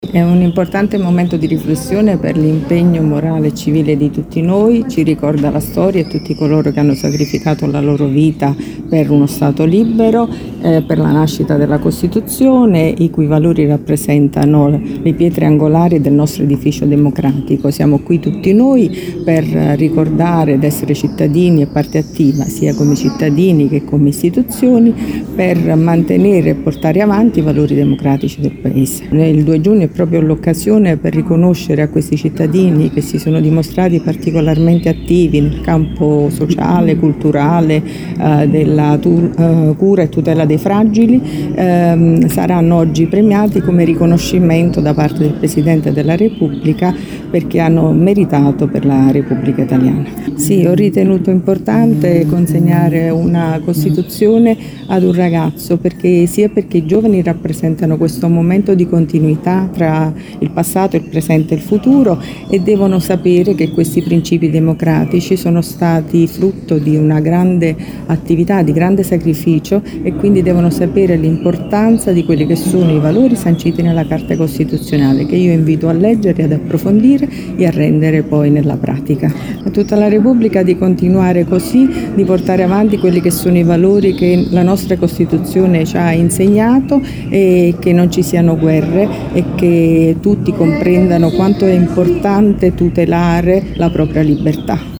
LATINA –  Ci sono anche  gli alunni delle scuole primarie e secondarie del capoluogo, e un gruppo di bambini ucraini, tra gli 8 e i 16 anni, figli di poliziotti deceduti durante l’attuale conflitto, in piazza della Libertà dove si è aperta la cerimonia del 2 giugno, 79° Festa della Repubblica.
Queste le sue parole a margine della manifestazione
prefetta-ciaramella-2-giugno-2025.mp3